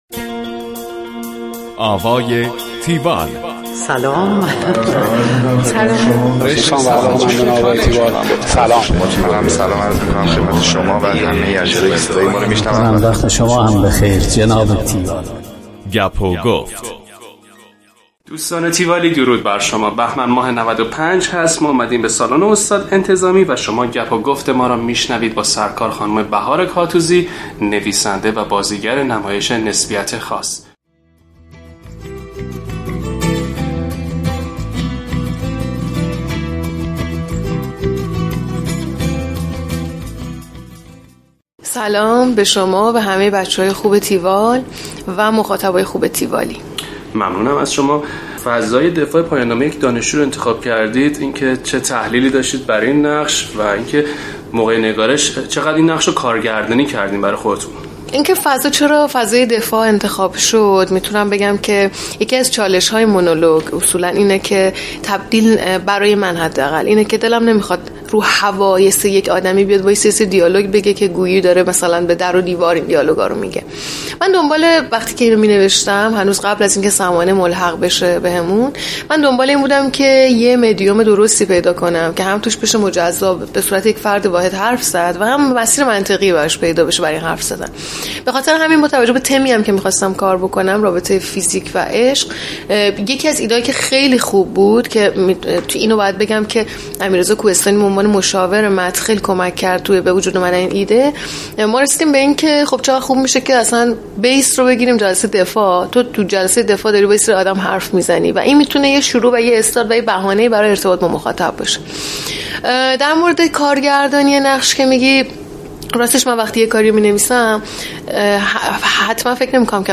گفتگوی تیوال